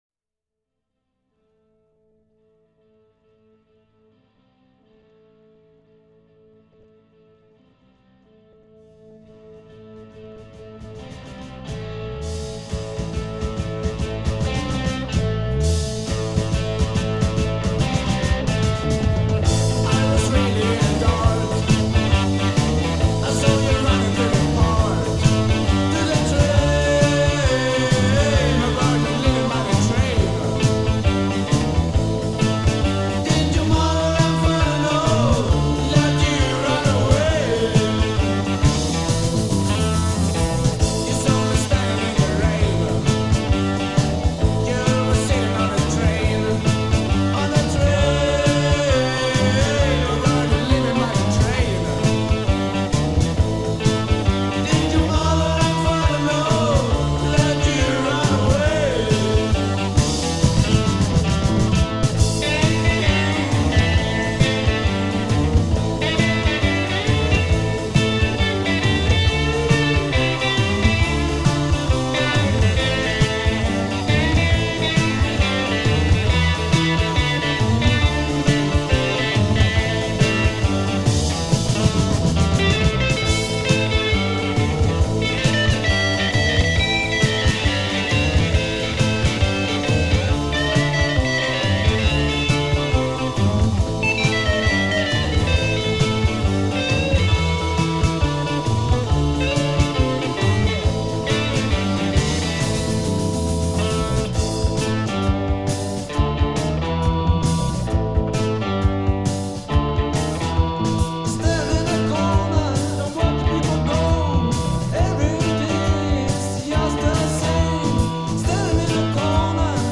March 13-14, 1982 (From pure old vinyl record)